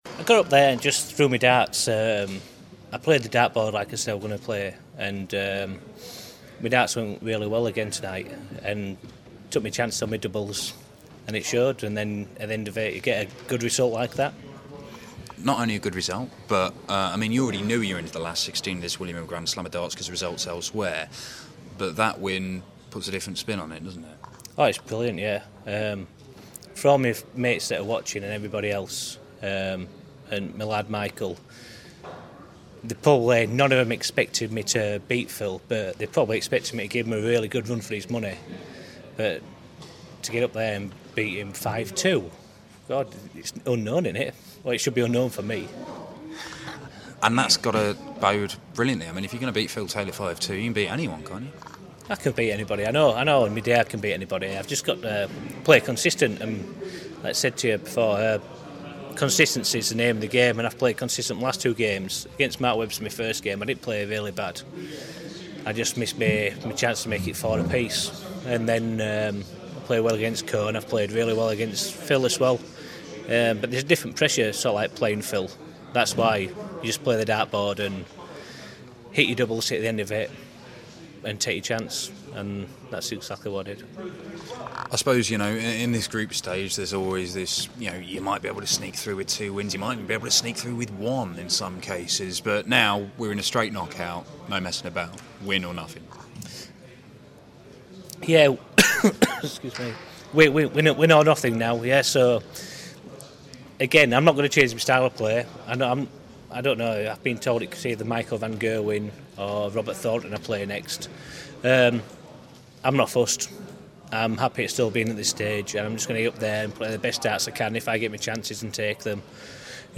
William Hill GSOD - Waites Interview (3rd game)